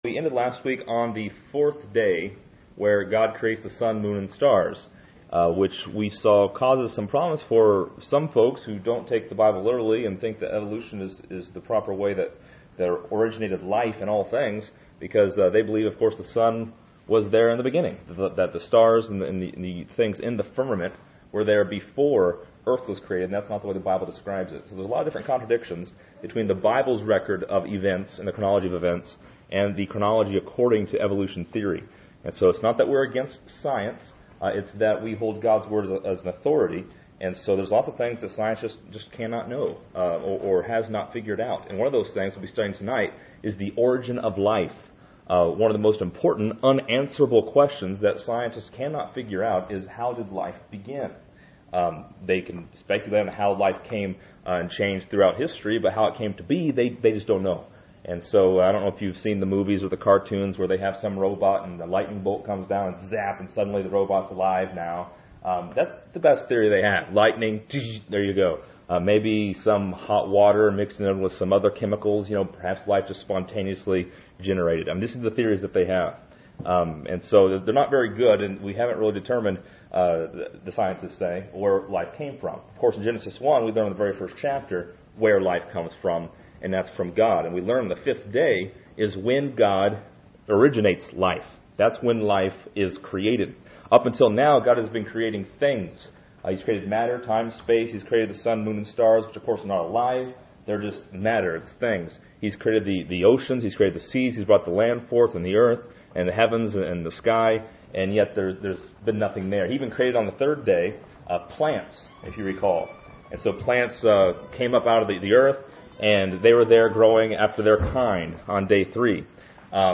This lesson is part 06 in a verse by verse study through Genesis titled: The Origin of Life.